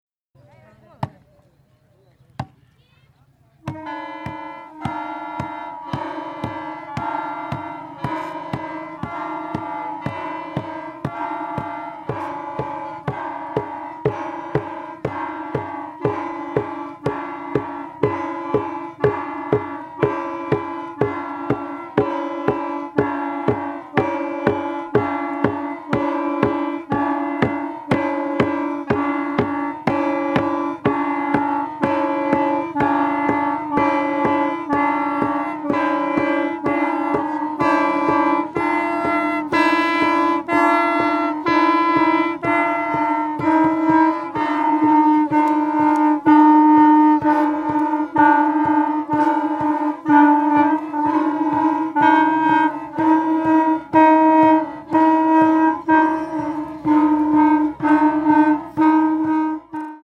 アジアの農村的、山岳的な風情がとてもイイですね！